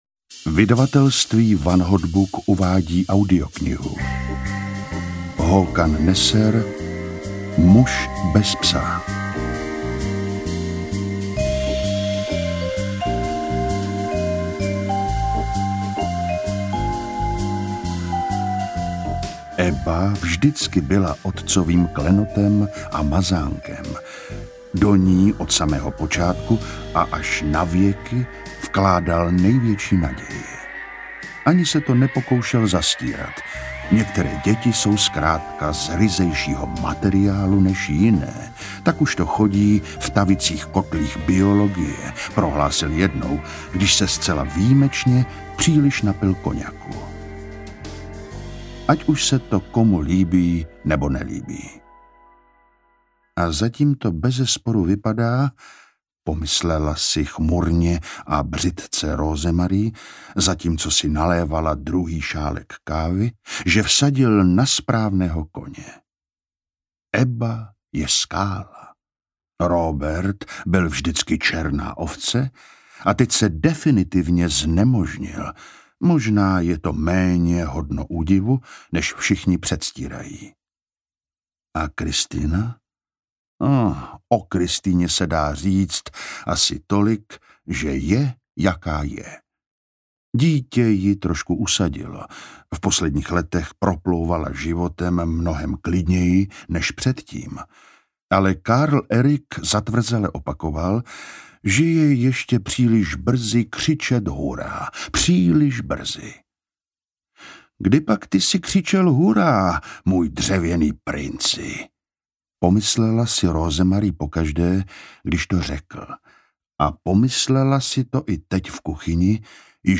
Muž bez psa audiokniha
Ukázka z knihy